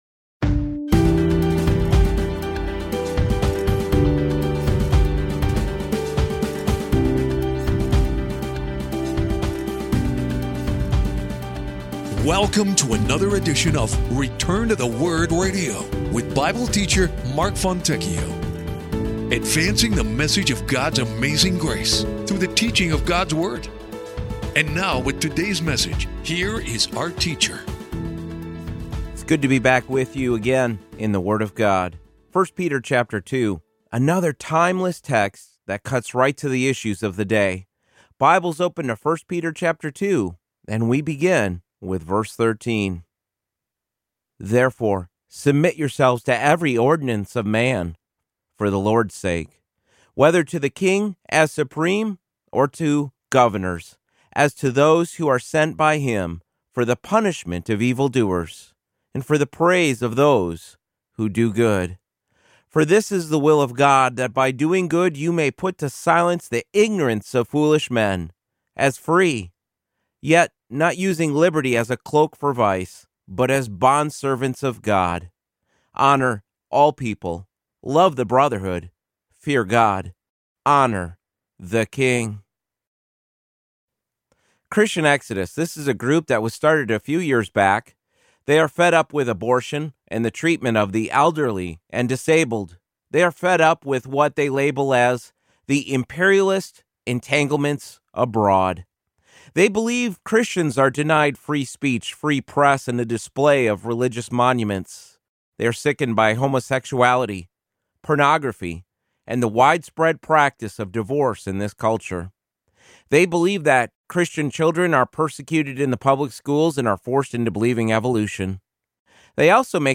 The timeless principles of 1 Peter 2:13-17 guide the Church to a better understanding of the path we should take as the storm clouds gather once again. Bible Teacher